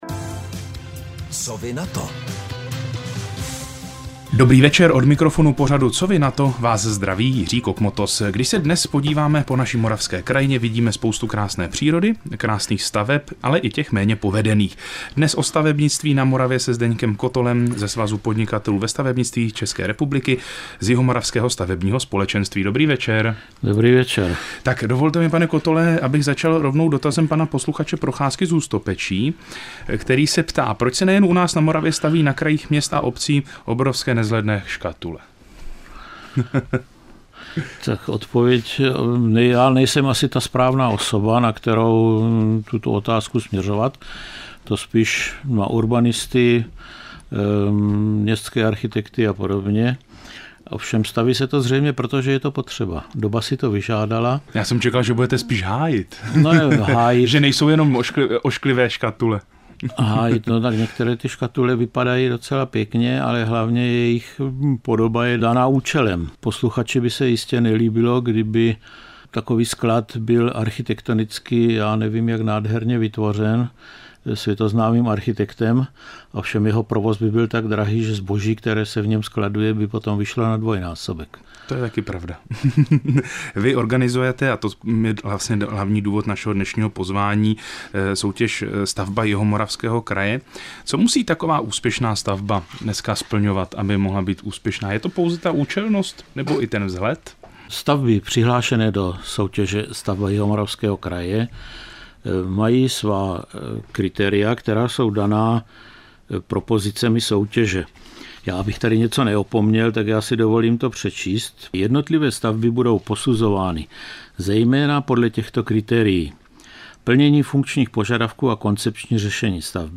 Rozhovor v �esk�m rozhlase
rozhovor_cr.mp3